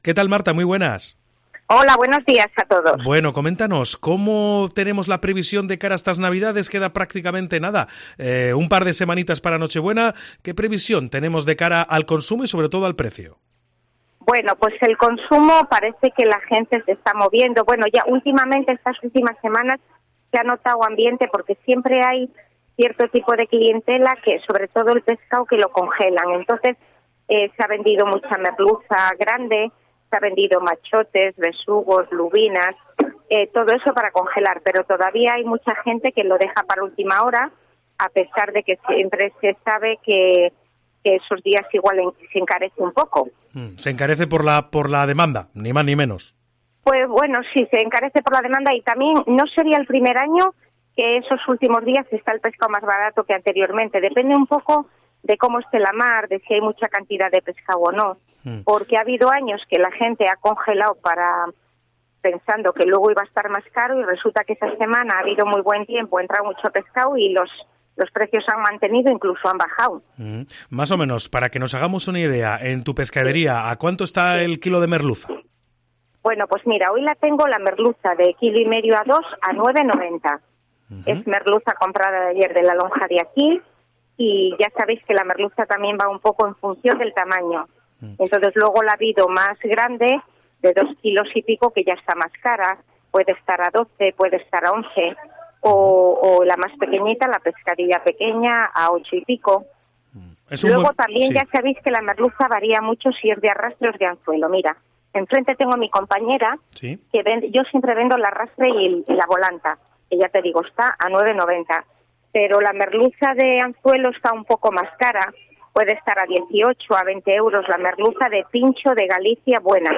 Se acercan las fechas claves para realizar la cesta de la compra navideña, y desde Cope Cantabria hemos querido acercarnos a uno de los puntos más solicitados por los santanderinos, el Mercado de la Esperanza.